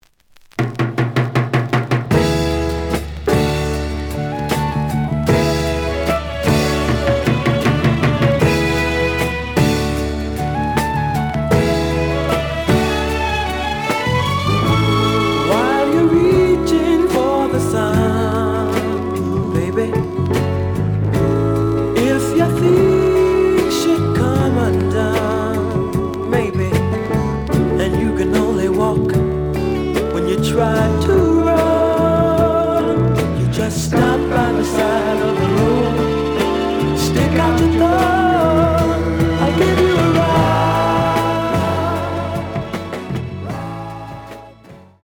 ●Format: 7 inch
●Genre: Soul, 70's Soul